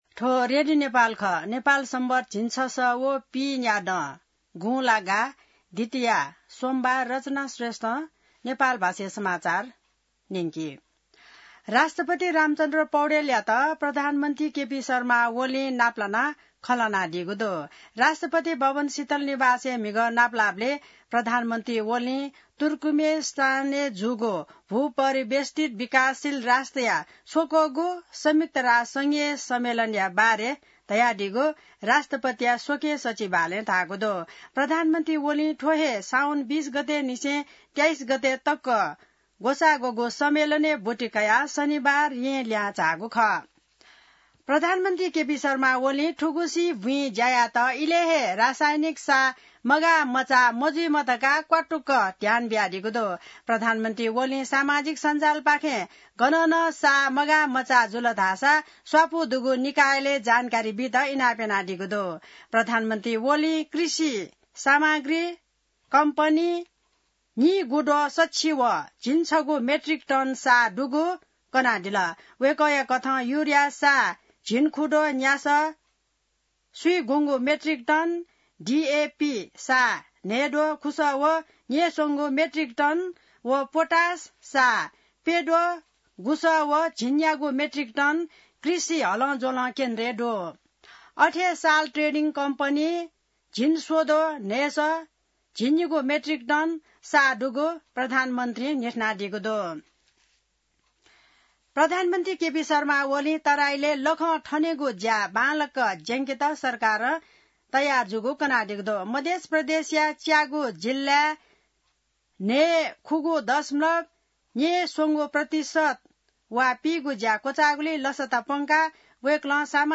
नेपाल भाषामा समाचार : २६ साउन , २०८२